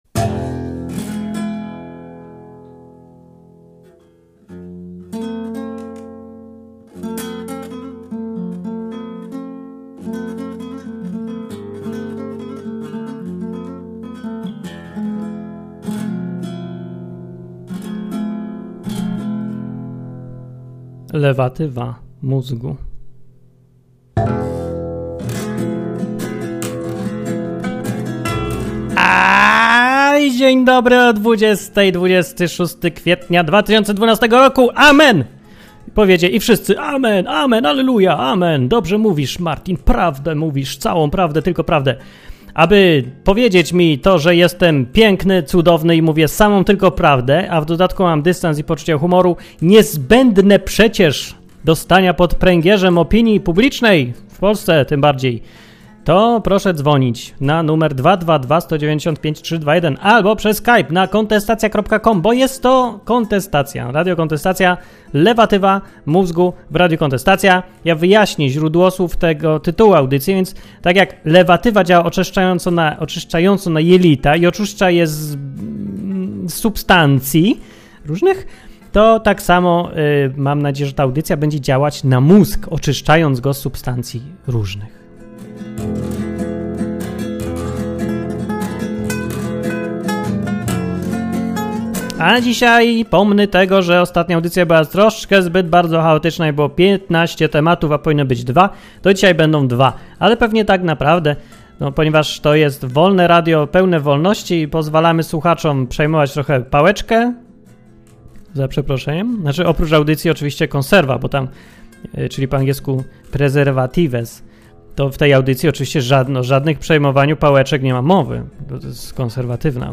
program satyryczno-informacyjny, który ukazywał się co tydzień w radiu KonteStacja
Były informacje, komentarze, słuchacze, wszystko co najlepsze.